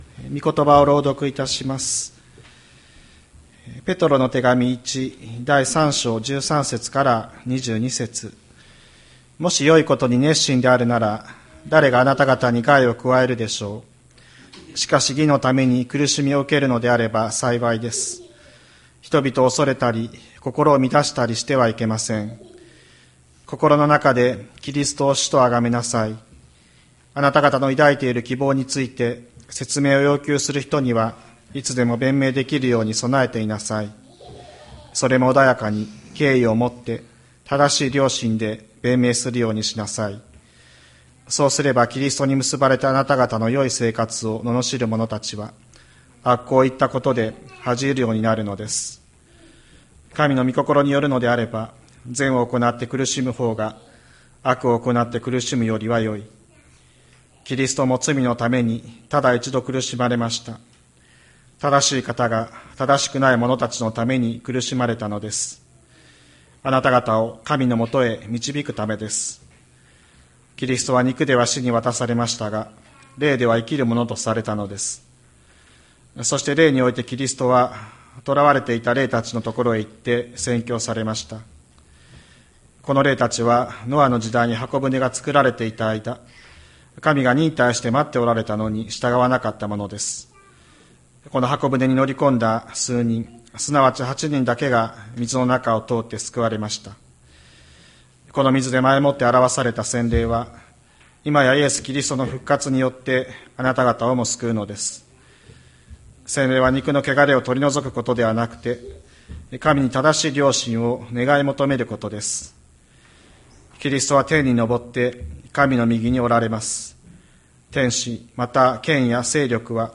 2024年04月07日朝の礼拝「陰府にくだるキリスト」吹田市千里山のキリスト教会
千里山教会 2024年04月07日の礼拝メッセージ。